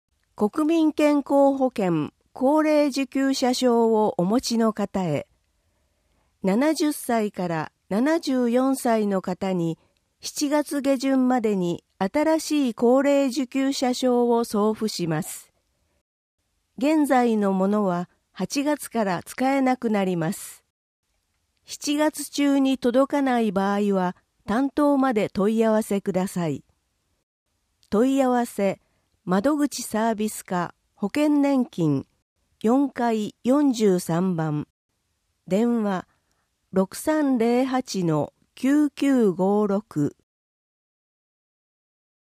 音訳版「よどマガ！」（令和5年7月号）